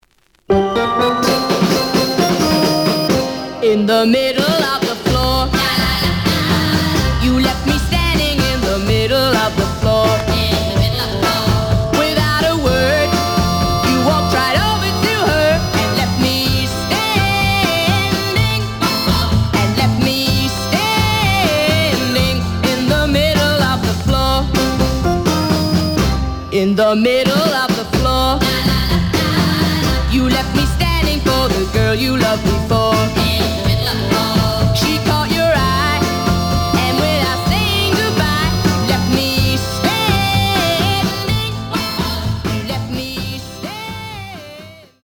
The audio sample is recorded from the actual item.
●Genre: Rhythm And Blues / Rock 'n' Roll
Slight damage on both side labels. Plays good.)